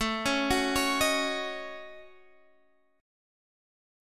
A6b5 chord